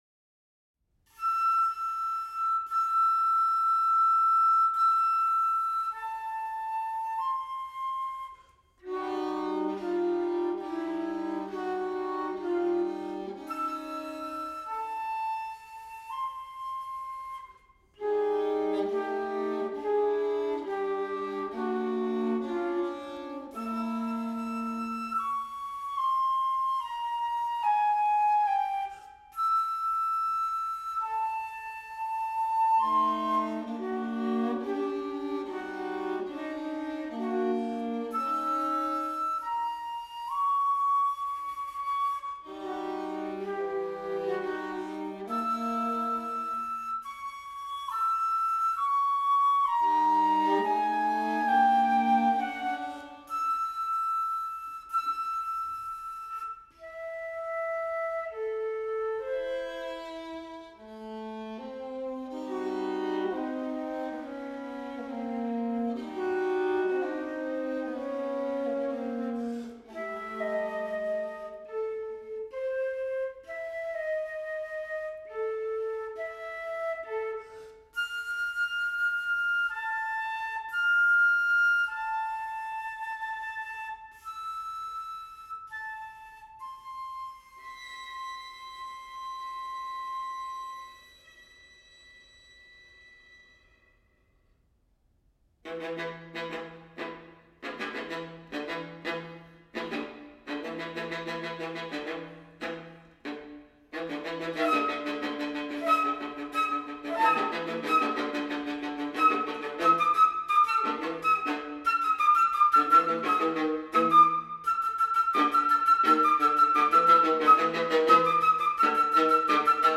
alto
flûte, flûte en sol, piccolo
En effet, ces deux instruments peuvent s'opposer de part leurs timbres, ou au contraire se rassembler entre le grave de la flûte et le medium et l'aigu de l'alto, permettant presque d'évoquer un instrument unique.
La pièce débute par un passage où un arpège mineur se mêle à des doubles cordes en sourdine de l'alto. La partie centrale met en lumière l'opposition que j'ai évoquée entre les deux instruments, interrompue par une reprise de l'arpège mineur au piccolo.